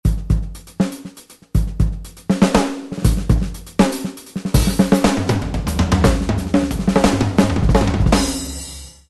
ソフトウェアのドラム音源BFD2。
♪いいですねぇ。